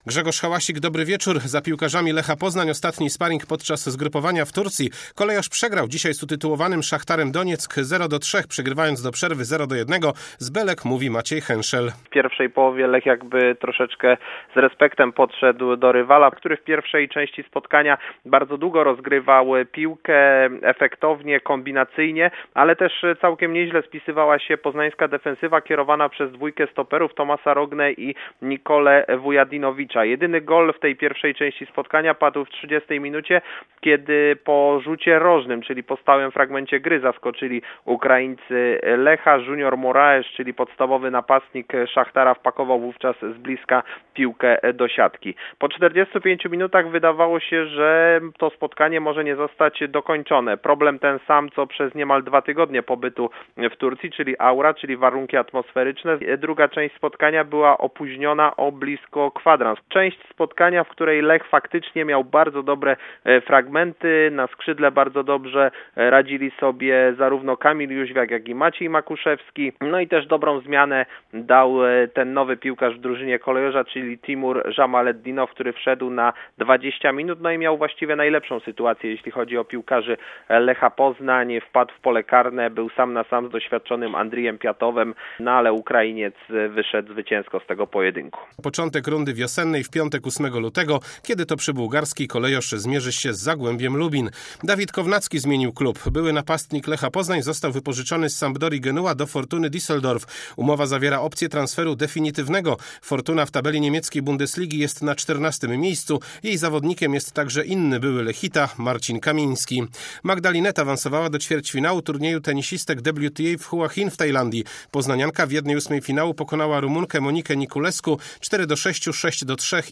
31.01. serwis sportowy godz. 19:05